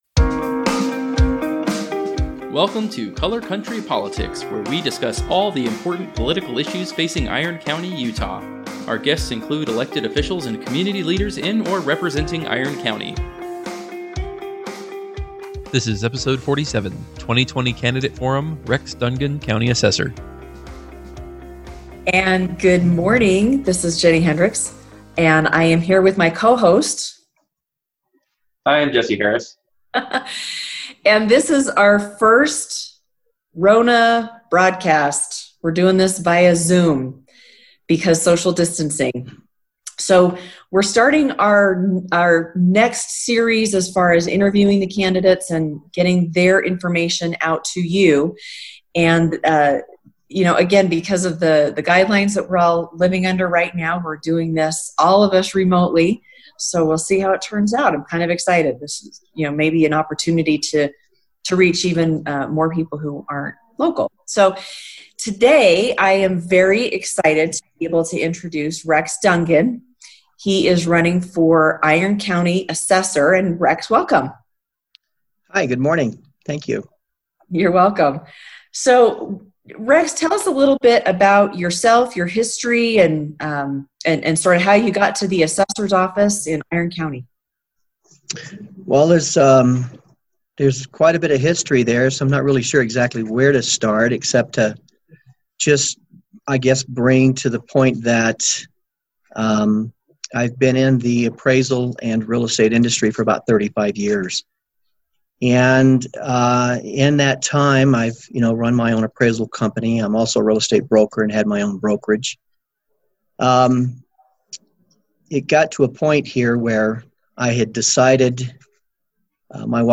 2020 Candidate Forum